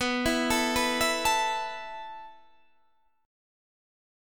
B7sus4 Chord